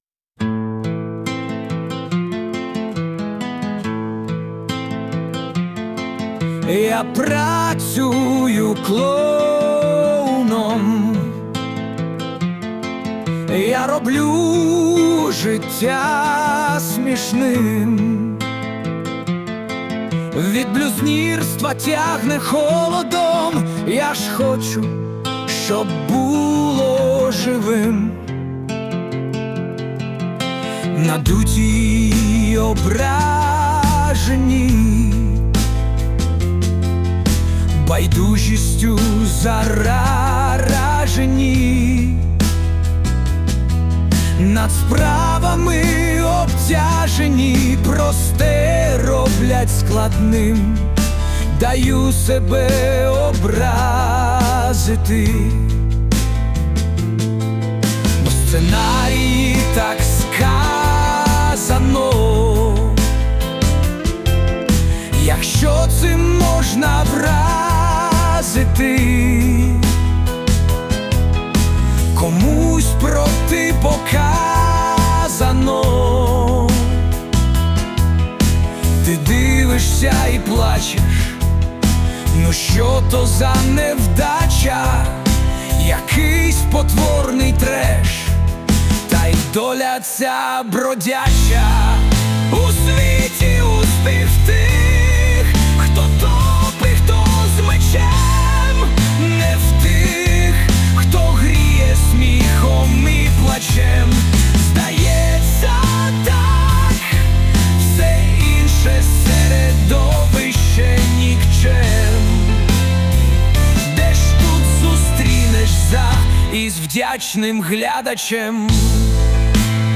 Кавер на власне виконання.
СТИЛЬОВІ ЖАНРИ: Ліричний